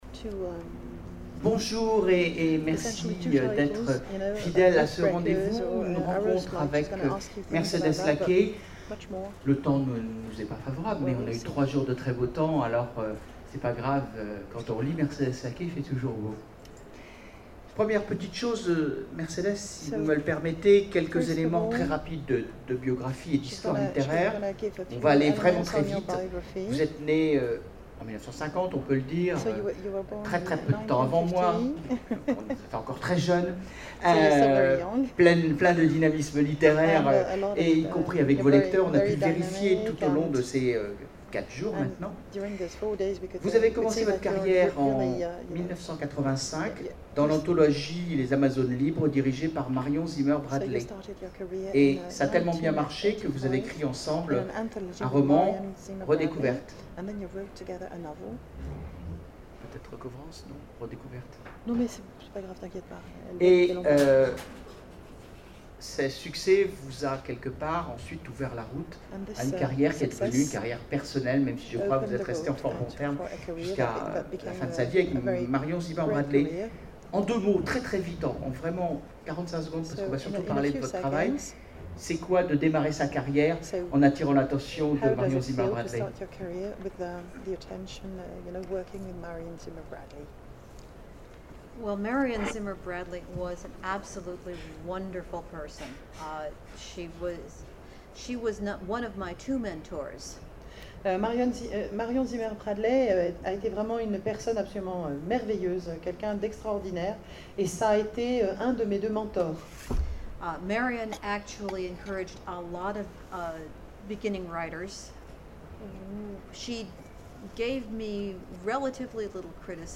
Imaginales 2012 : Conférence Entretien avec... Mercedes Lackey
- le 31/10/2017 Partager Commenter Imaginales 2012 : Conférence Entretien avec... Mercedes Lackey Télécharger le MP3 à lire aussi Mercedes Lackey Genres / Mots-clés Rencontre avec un auteur Conférence Partager cet article